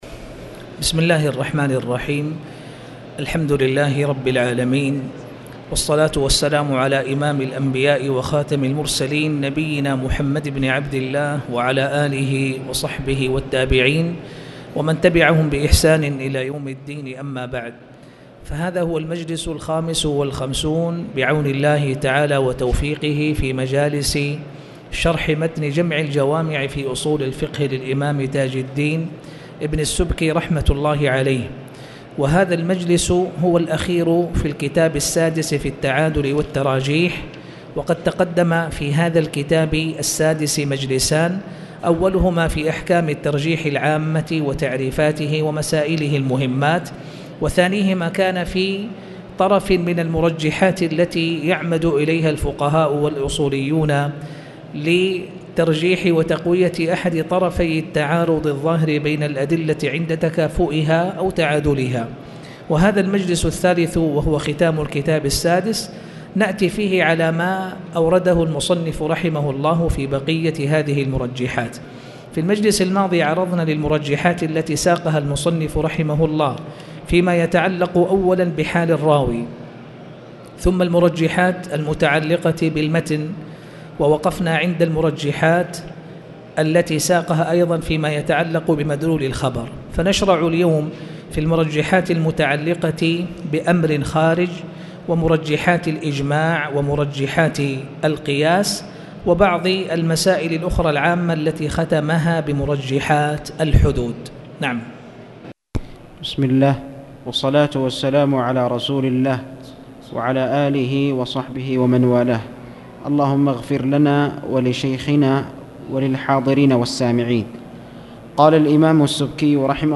تاريخ النشر ٢٥ جمادى الأولى ١٤٣٨ هـ المكان: المسجد الحرام الشيخ